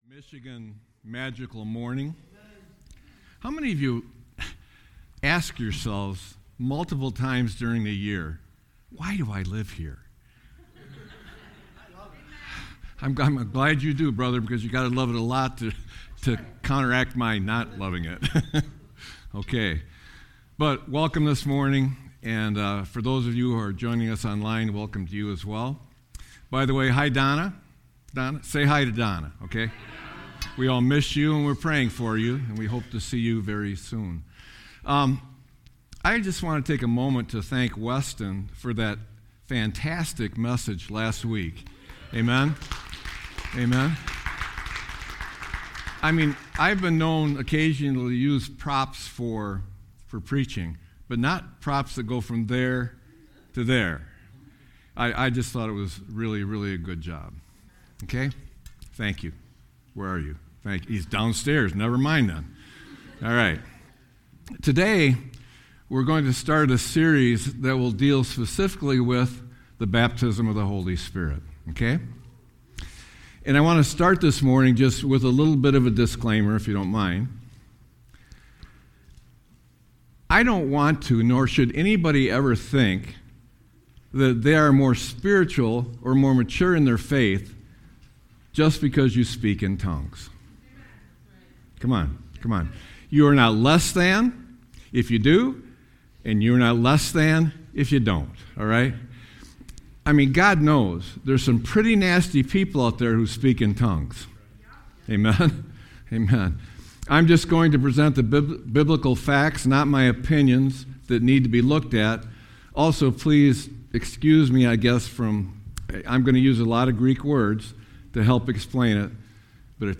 Sermon-1-25-26.mp3